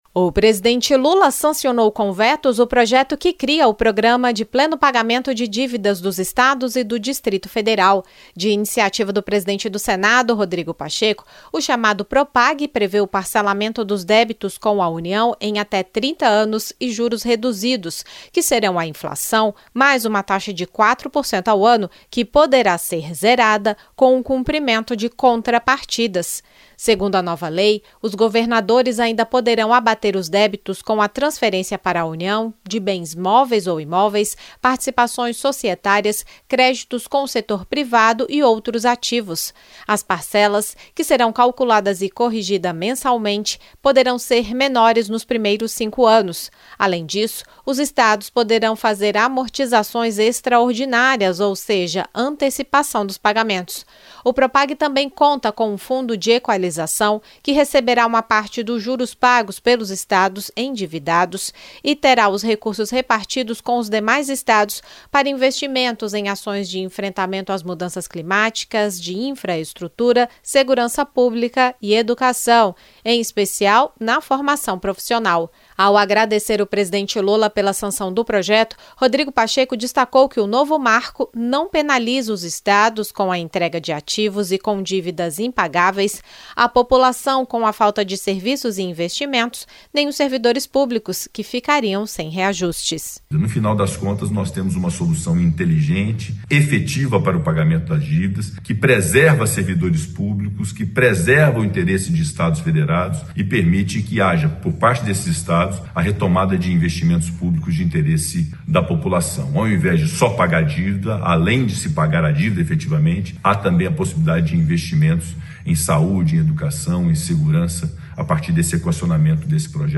Ao agradecer o presidente Lula pela sanção do Programa de Pleno Pagamento de Dívidas dos Estados e do Distrito Federal, o presidente do Senado, Rodrigo Pacheco, destacou que se trata de um marco que resolve um problema crônico de débitos impagáveis.
Já o líder do governo no Congresso Nacional, senador Randolfe Rodrigues (PT-AP), ressaltou que os vetos apostos ao projeto não prejudicam a essência do Propag.